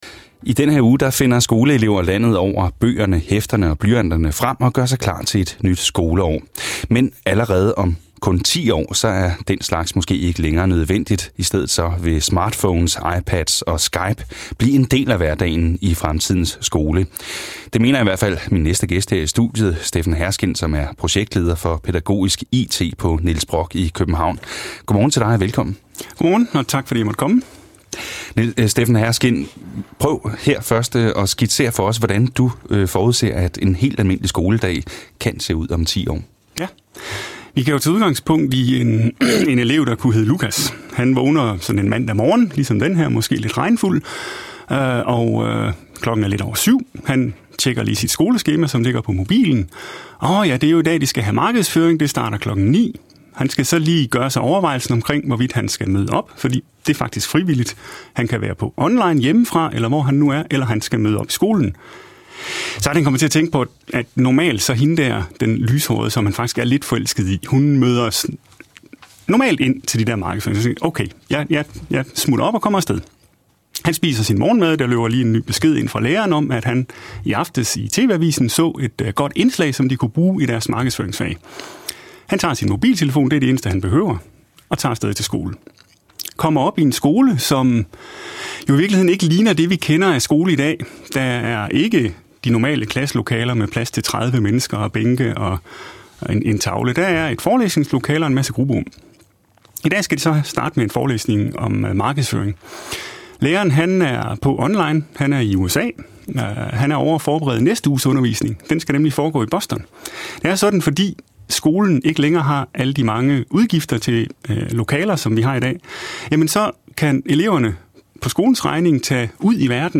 Fra radioens P1, mandag 9. august 2010: